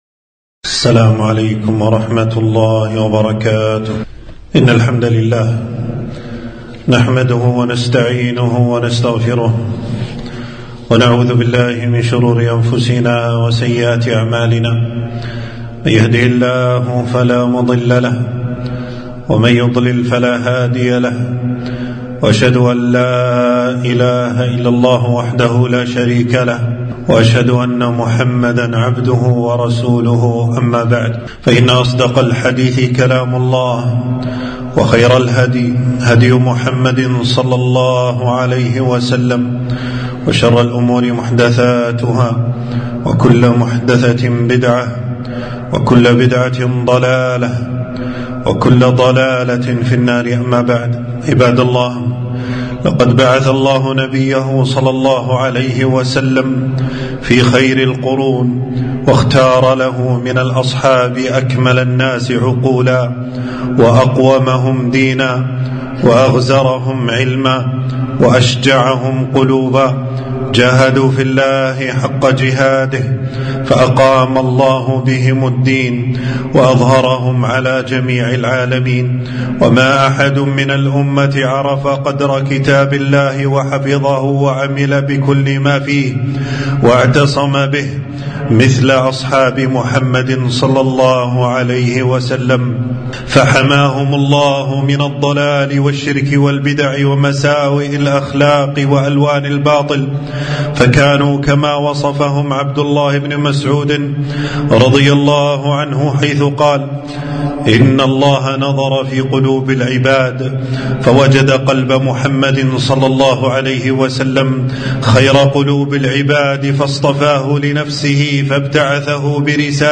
خطبة - فضائل الصحابي الجليل معاوية بن أبي سفيان رضي الله عنهما